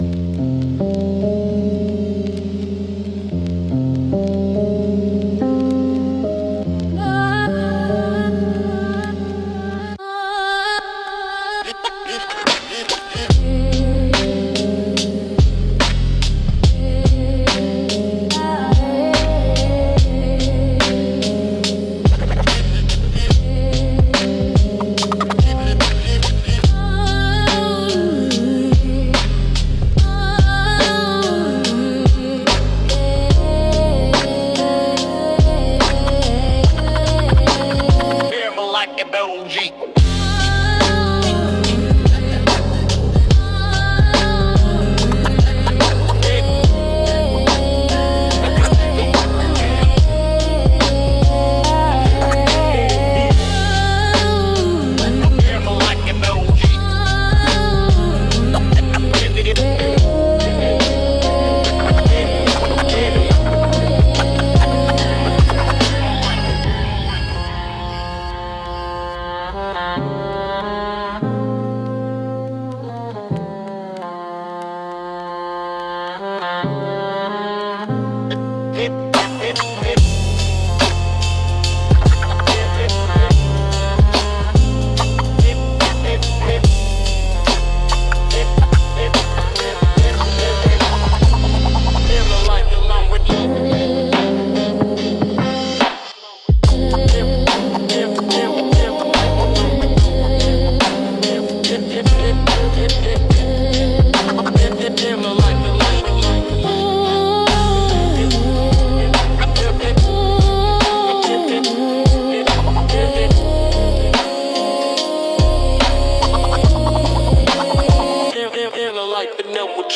Instrumental - Distorted Lullaby for the Watched